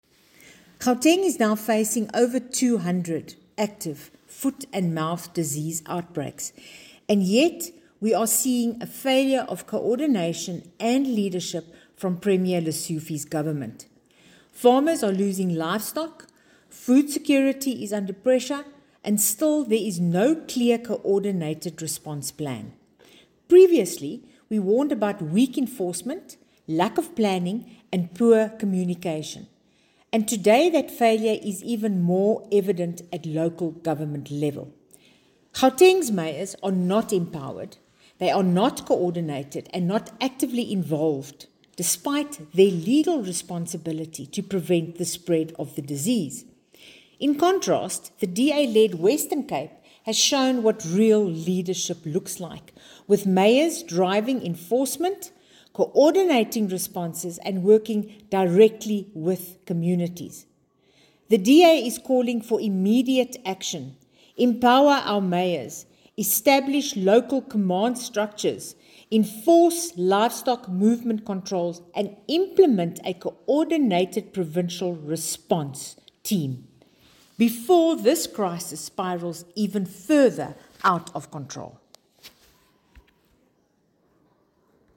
Issued by Bronwynn Engelbrecht MPL – DA Gauteng Shadow MEC for Agriculture